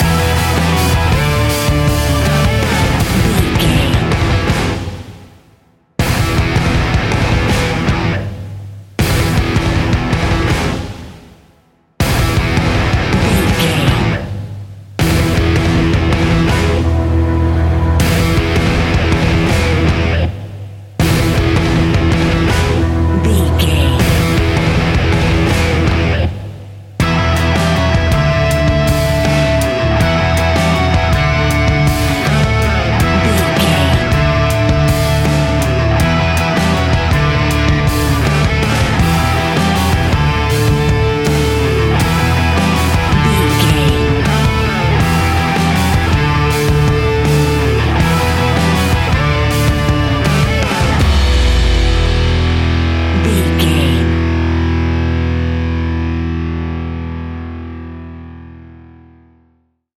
Ionian/Major
E♭
hard rock
distortion